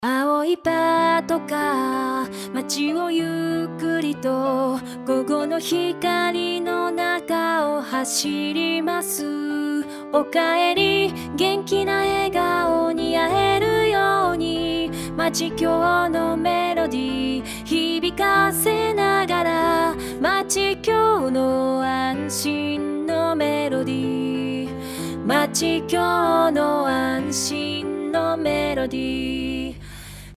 B_aopatonouta_slow.mp3